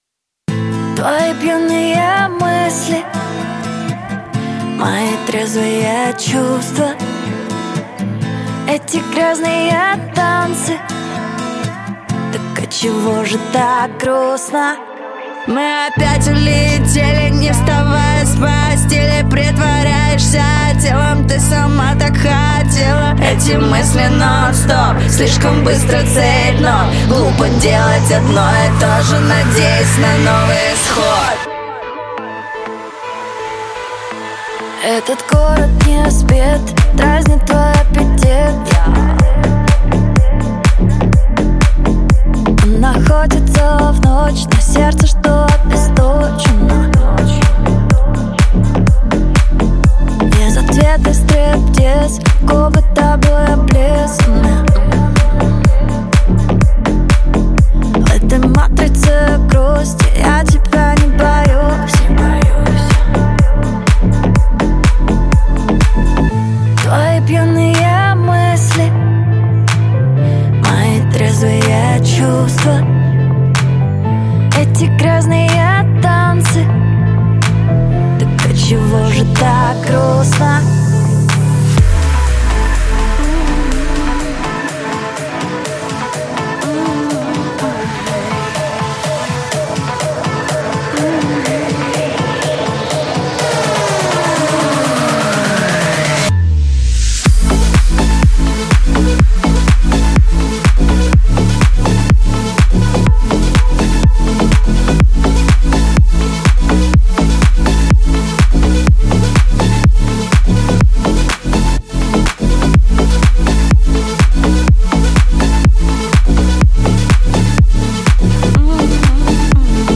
яркая поп-песня
мощный вокал и запоминающуюся мелодию